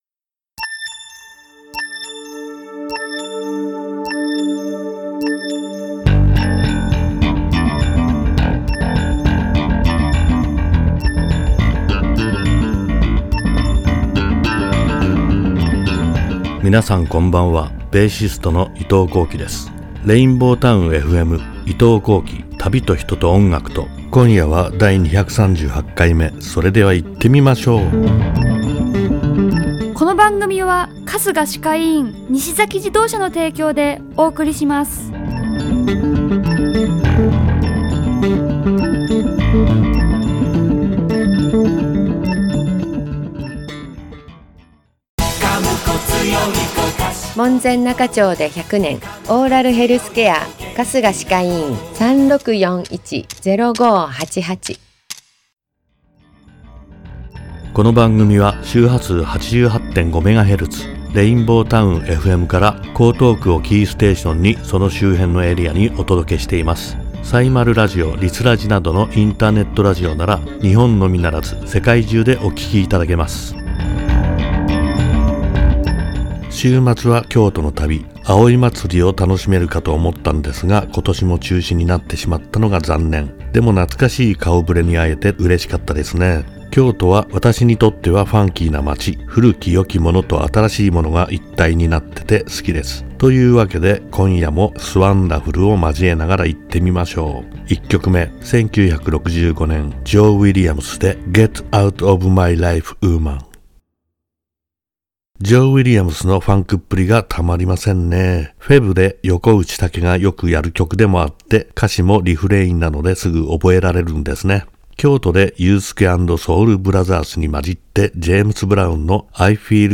※アーカイブ・オンデマンドでは、トーク内容のみで楽曲はかけておりません。ご了承ください。